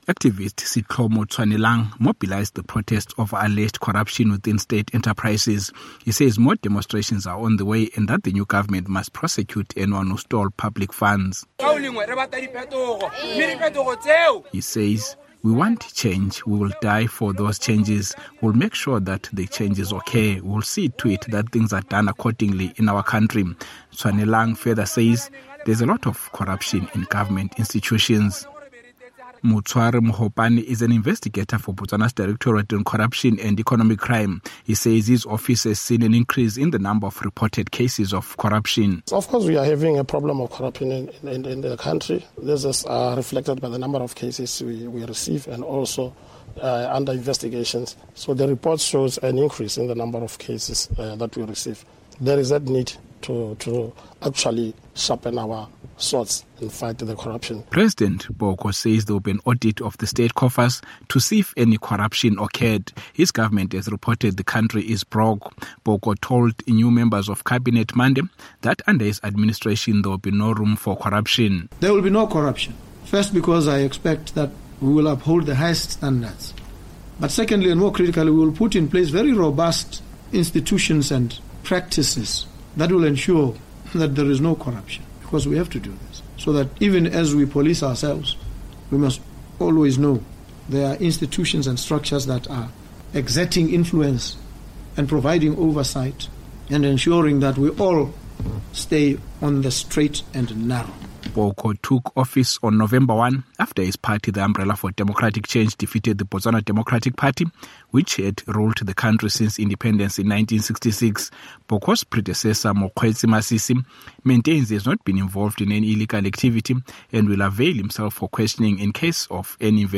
reports from the capital, Gaborone.